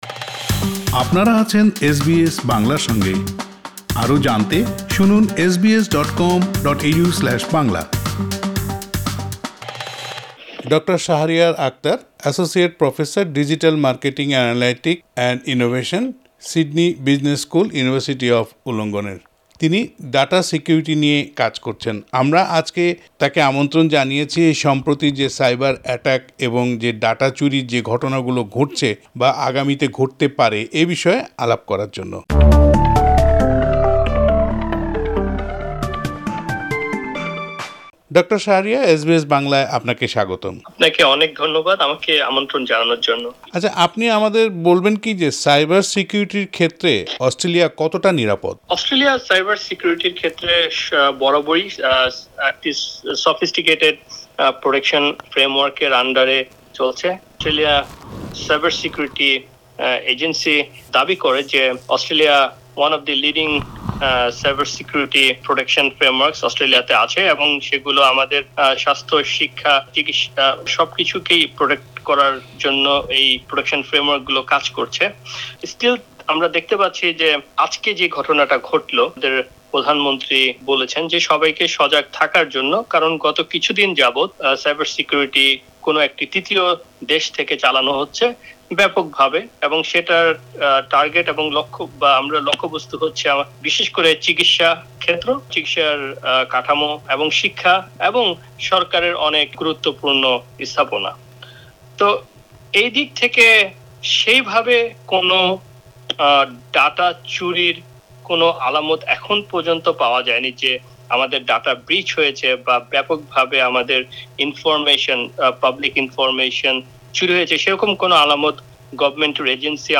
সাক্ষাৎকারটি